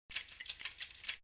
CHQ_FACT_switch_depressed.mp3